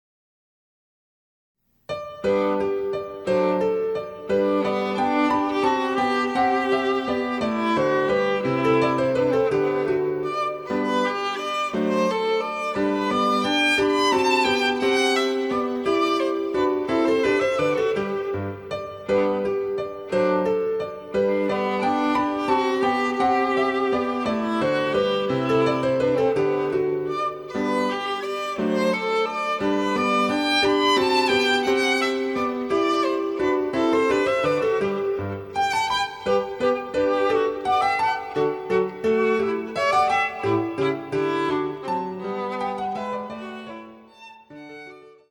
ヴァイオリン演奏
(1)各楽章につきモダンピッチ(A=442Hz)の伴奏